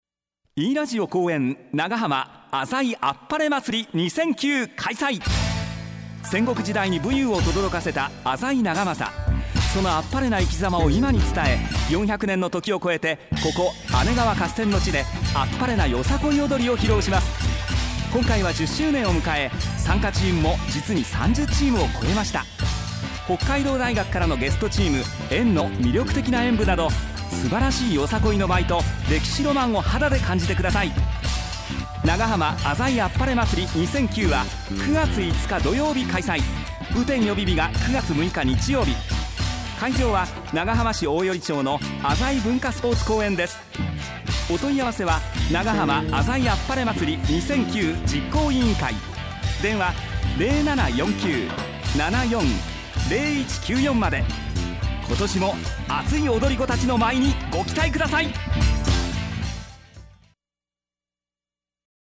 滋賀県のFM放送　e-daio　でも毎日放送いただいています。
radio-pr.mp3